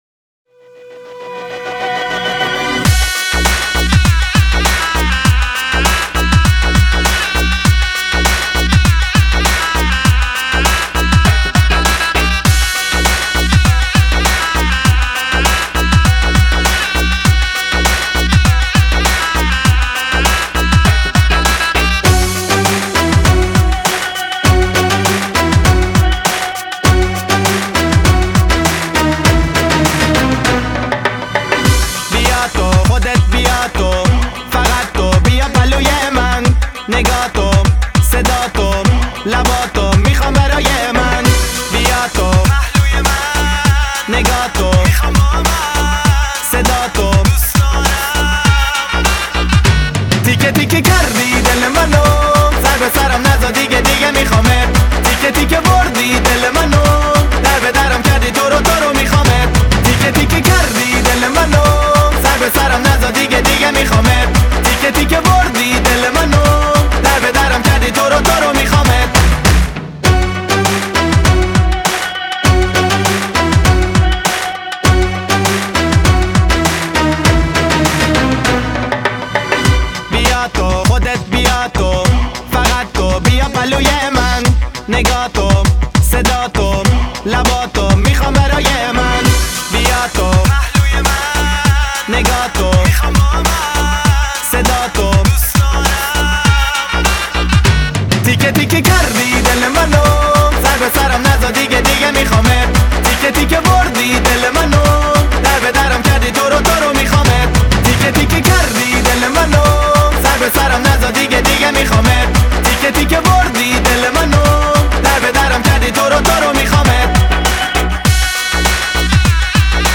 [20/6/2010]印度歌曲-动听的印度慢摇